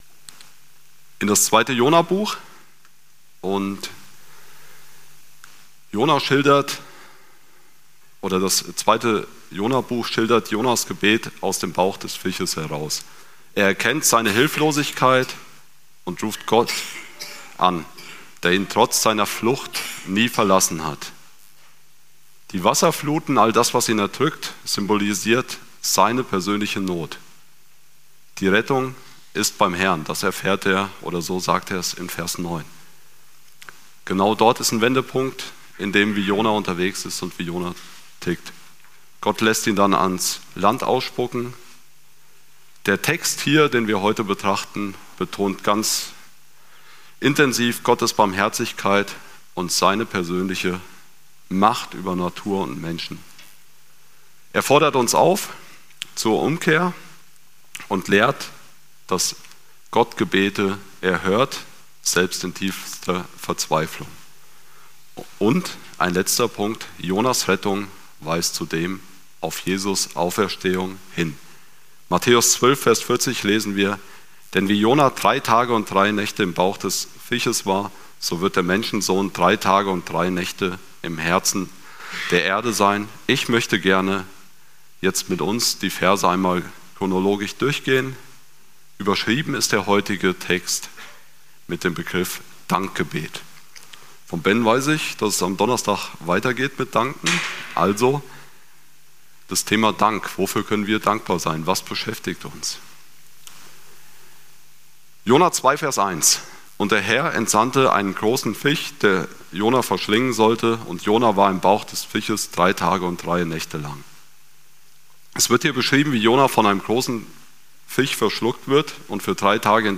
26.10.2025 Wortbetrachtung ~ Predigten - FeG Steinbach Podcast